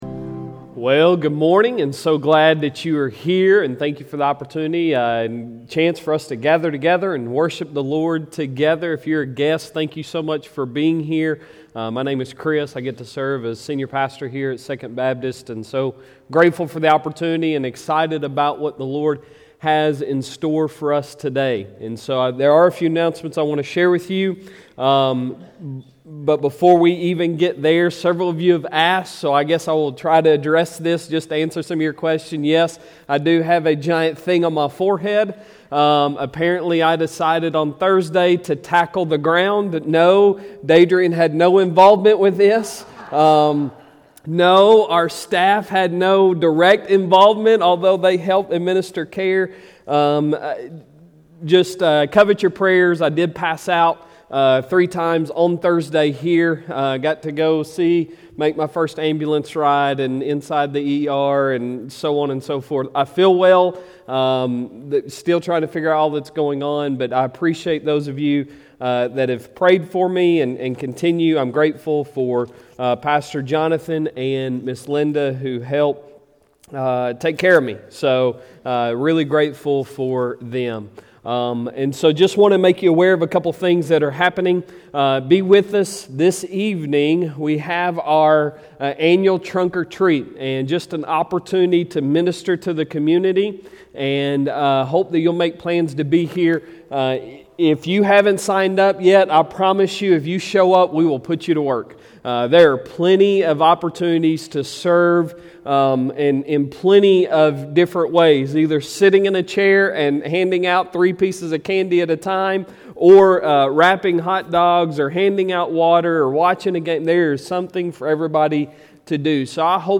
Sunday Sermon October 31, 2021